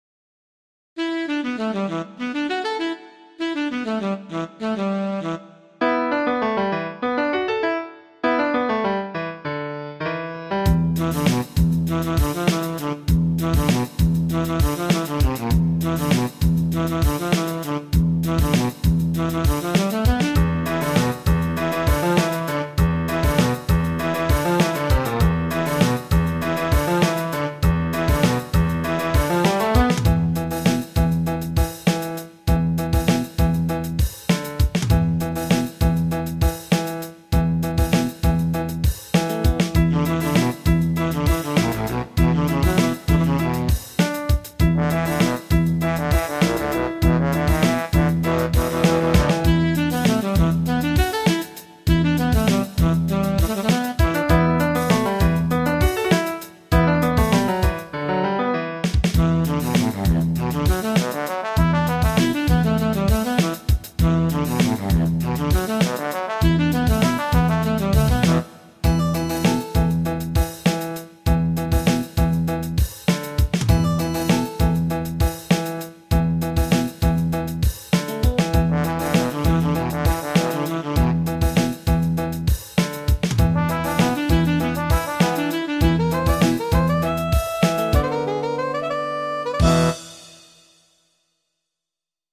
FUNK ROCK MUSIC ; JAZZ FUNK MUSIC